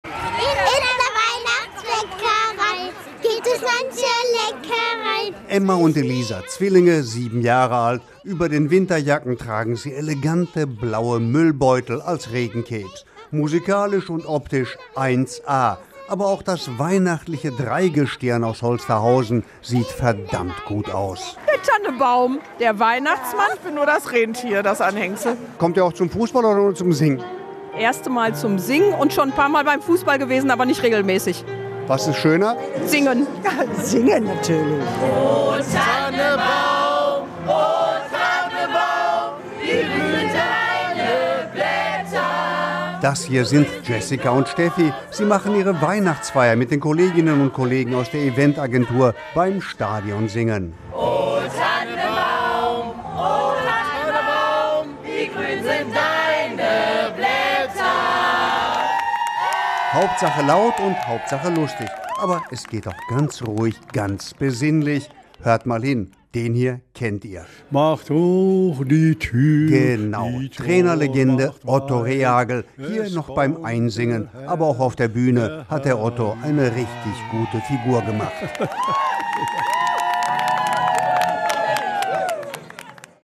Stadionsingen in Essen - Regencapes und gute Stimmung - Radio Essen
Trotz des Wetters war das Stadion gut besucht und die Stimmung war besonders schön.
stadionsingen2023-highlights.mp3